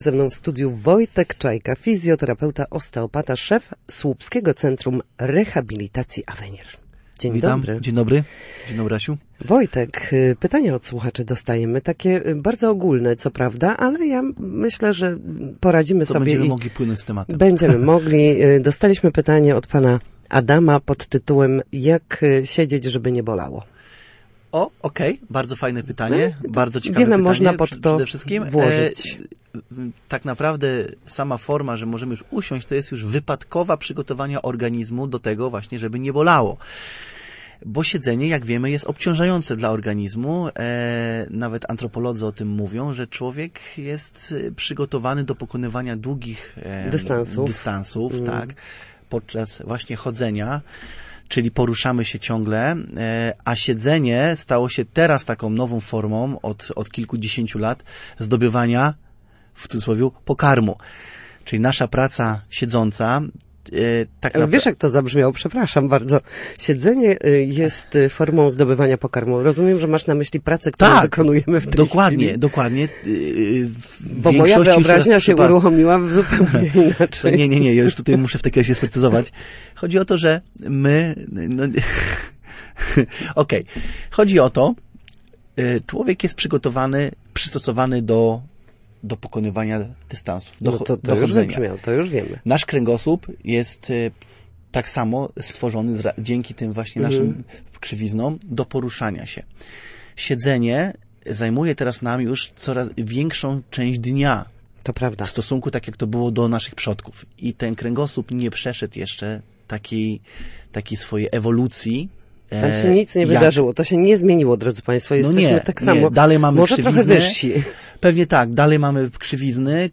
W każdą środę w popołudniowym Studiu Słupsk Radia Gdańsk dyskutujemy o tym, jak wrócić do formy po chorobach i urazach.